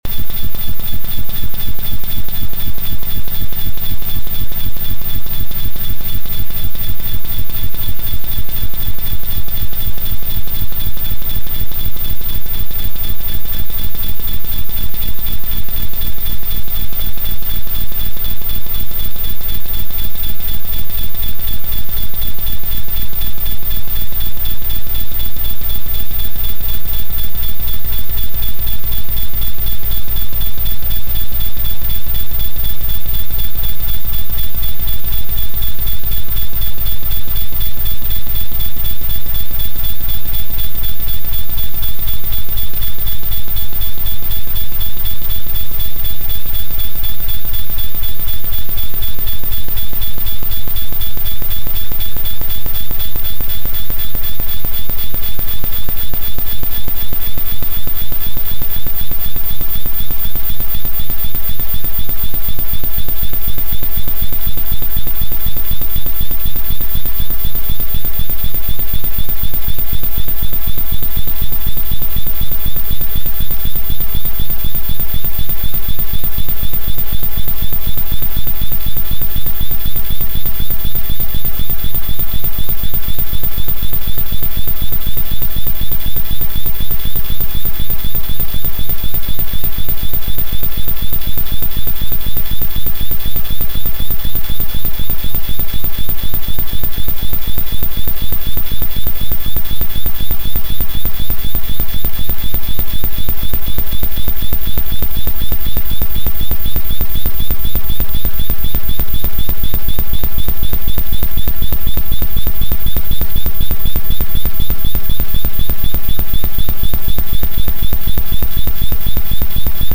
experimental, sound exploration, stereoscopic